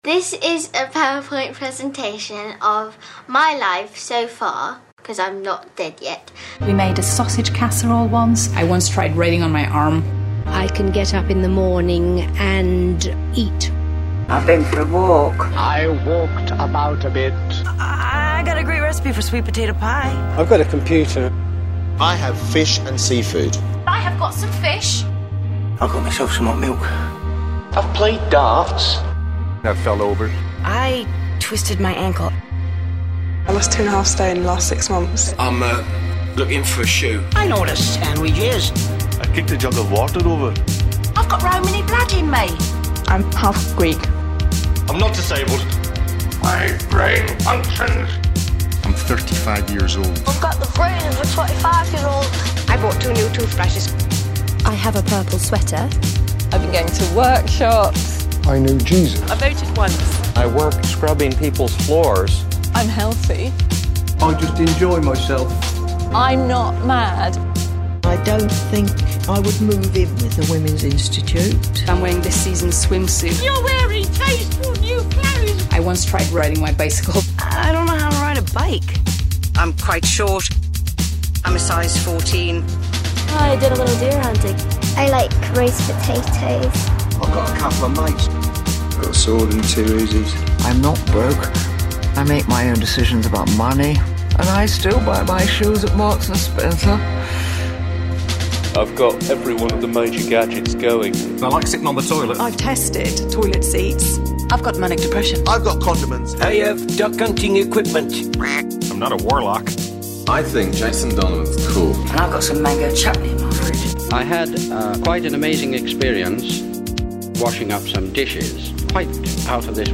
Genre: Electronic, Non-Music
Style: Comedy, Experimental, Musique Concrète